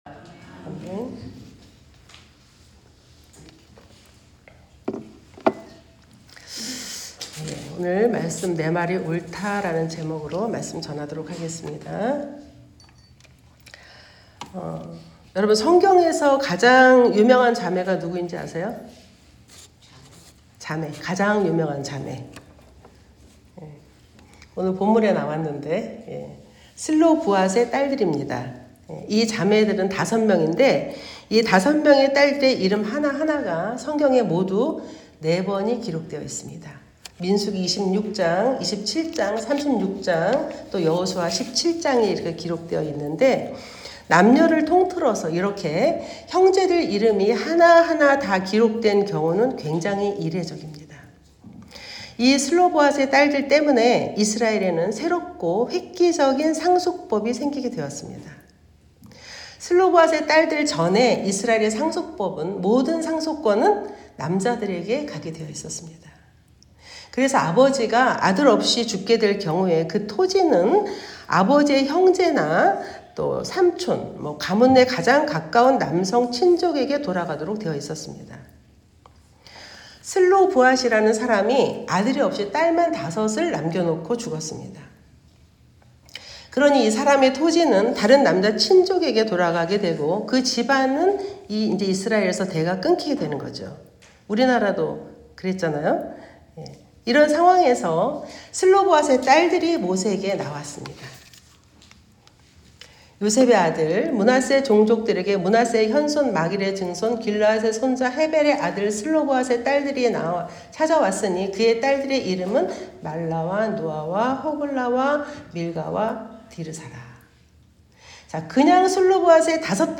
말씀